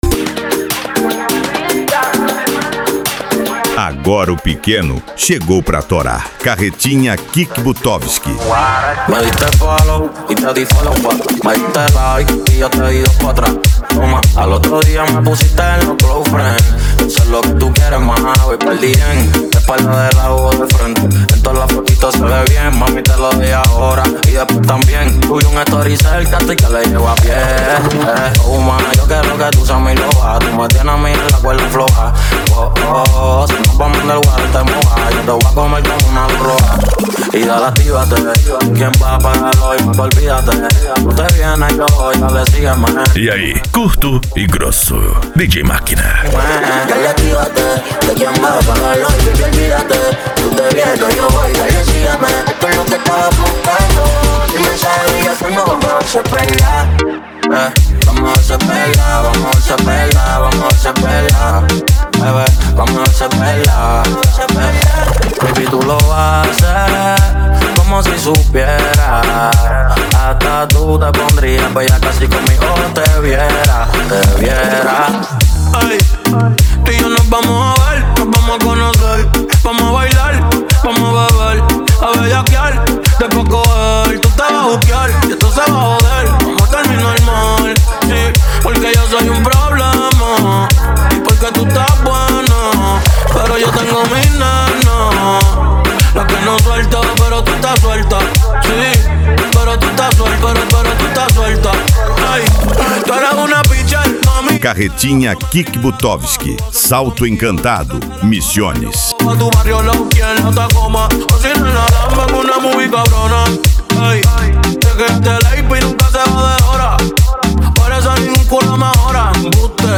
Bass
PANCADÃO
Remix